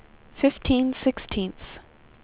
speech / tts / prompts / voices
number_17.wav